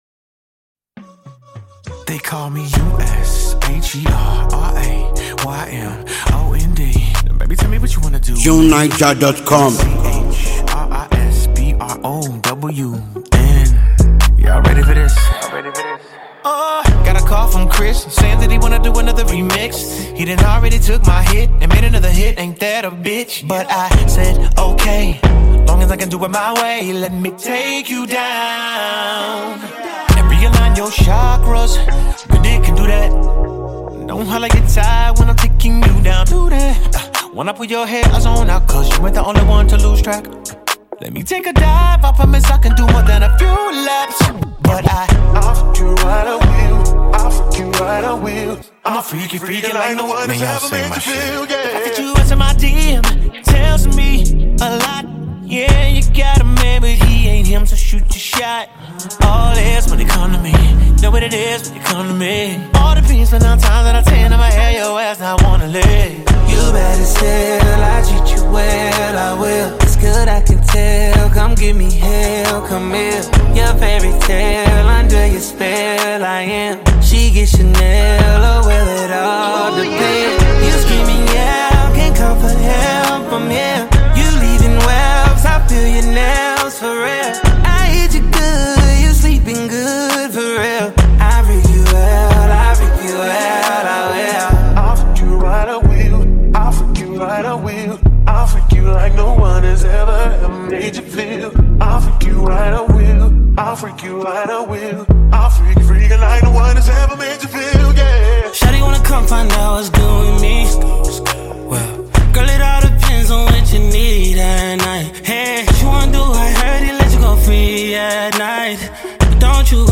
American R&B singer